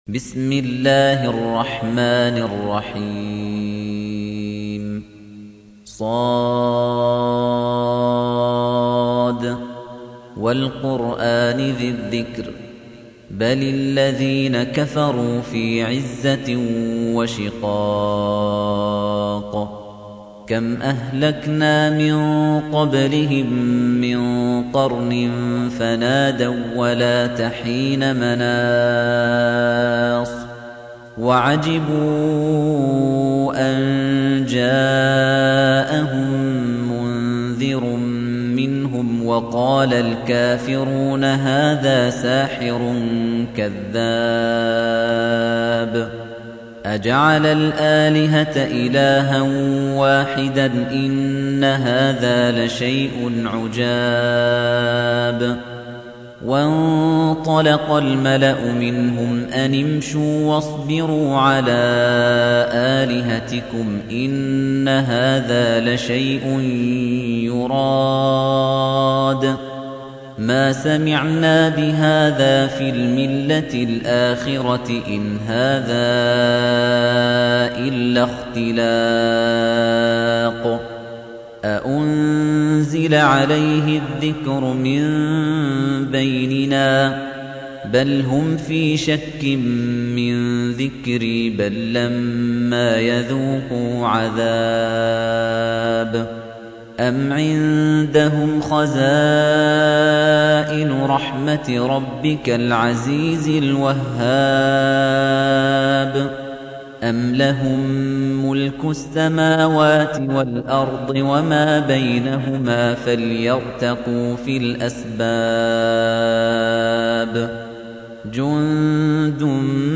Surah Repeating تكرار السورة Download Surah حمّل السورة Reciting Murattalah Audio for 38. Surah S�d. سورة ص N.B *Surah Includes Al-Basmalah Reciters Sequents تتابع التلاوات Reciters Repeats تكرار التلاوات